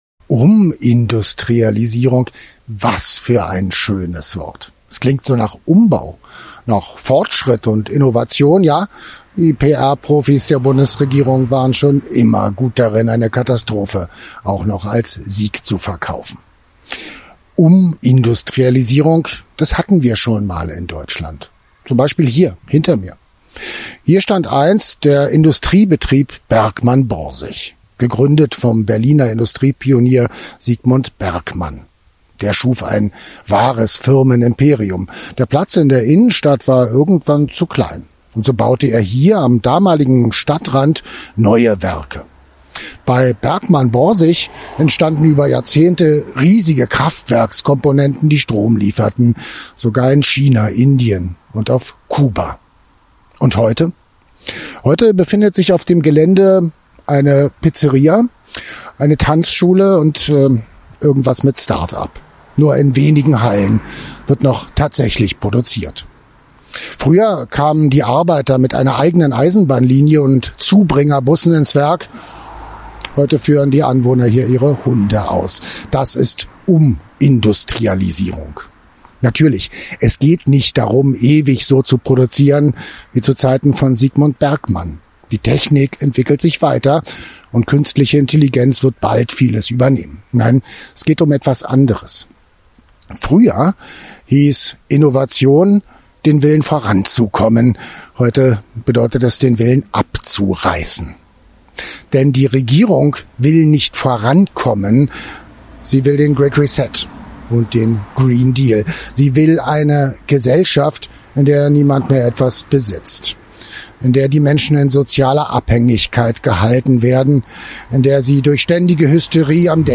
Entwicklung kein Zufall ist: ein Kommentar von